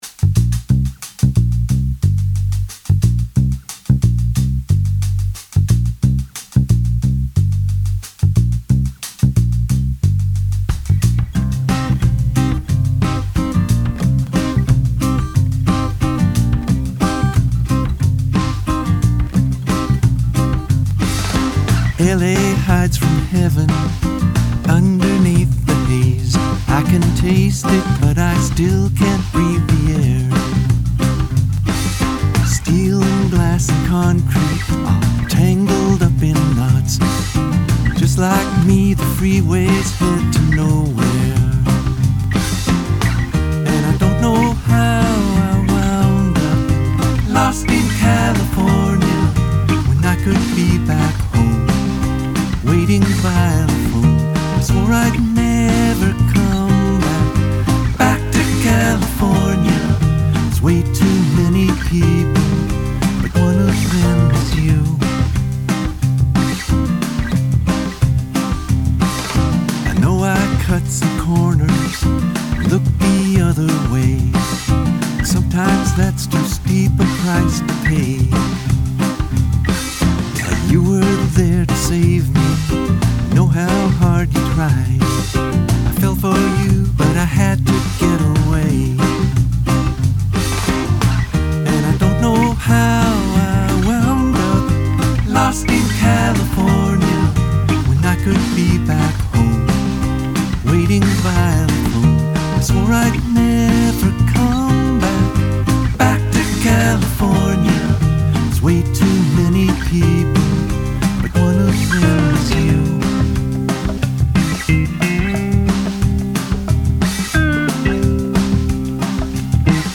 This is my first attempt at mastering a project -- I don’t know if it’s an improvement since I’m new to the process, but it was a fun learning adventure.